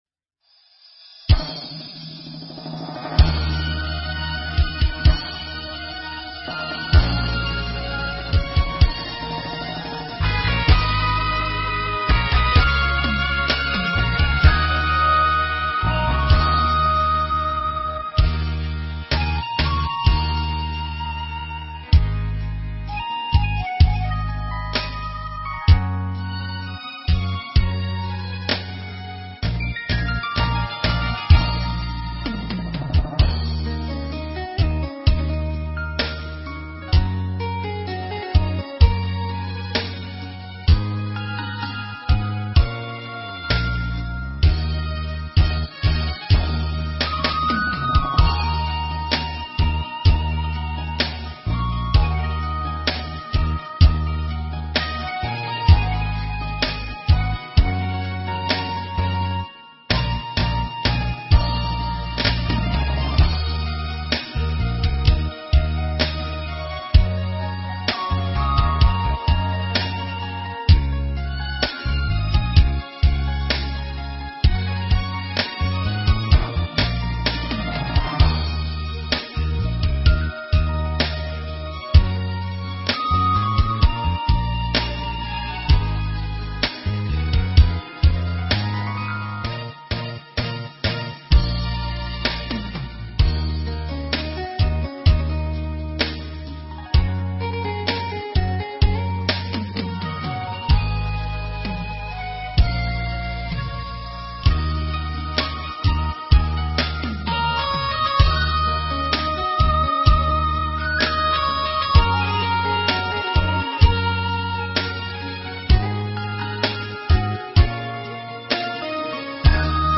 * 링크주소를 클릭하시면 반주곡 미리듣기를 하실 수 있습니다.